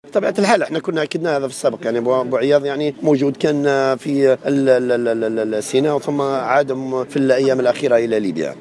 على هامش ندوة صحفية للمرصد التونسي لحقوق الإنسان و لجنة حكماء ليبيا